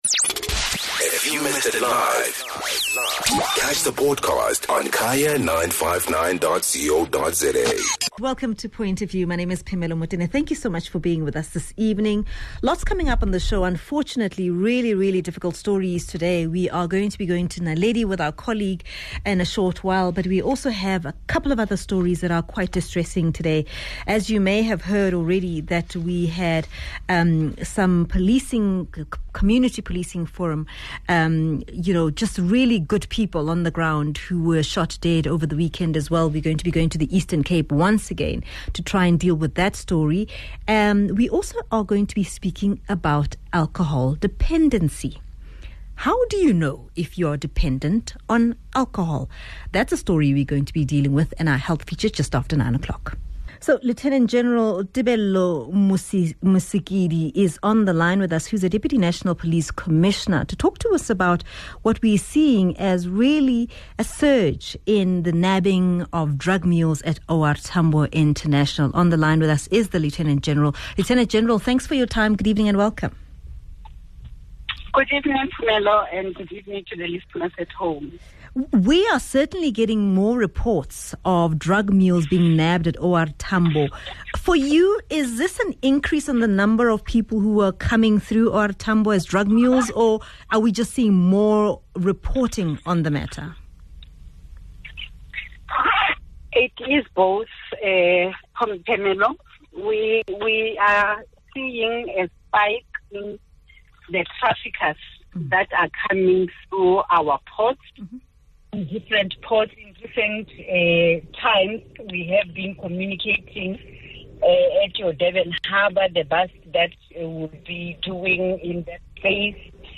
speaks to Deputy National Police Commissioner, Lieutenant General Tebello Mosikili about the recent spike in arrests.